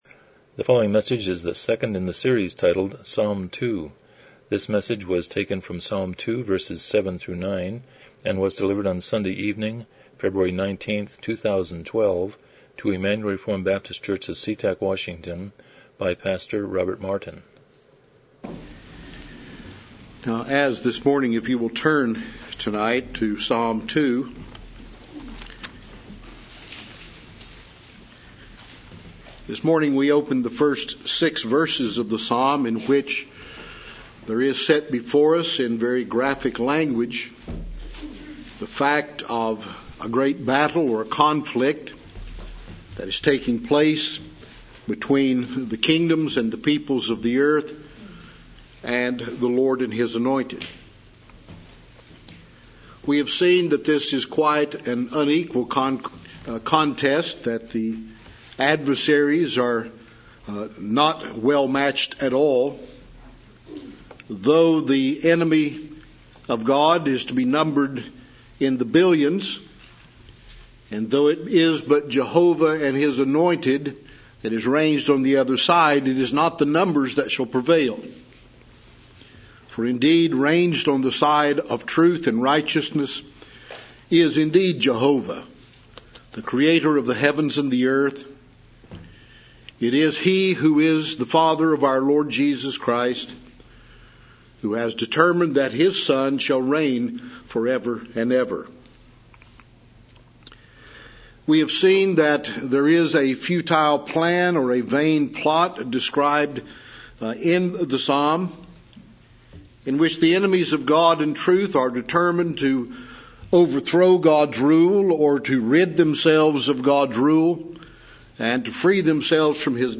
Psalm 2:7-9 Service Type: Evening Worship « Psalm 2